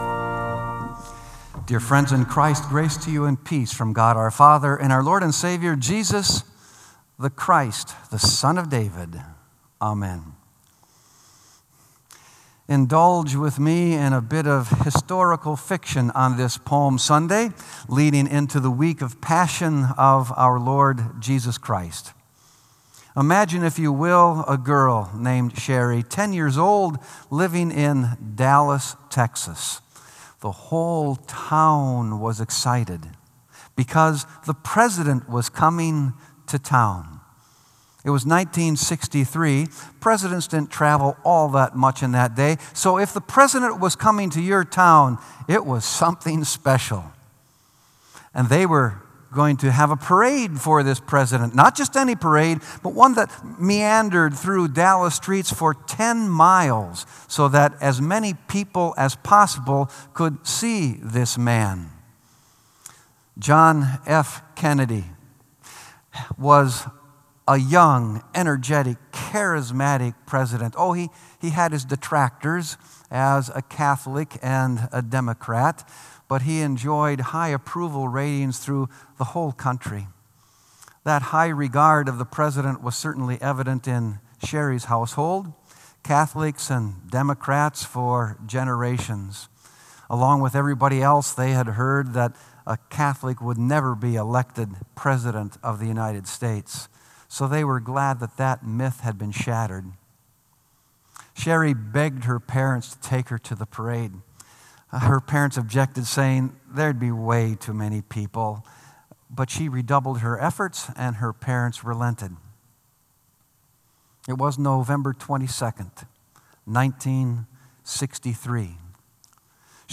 Sermon “Heights to Depths”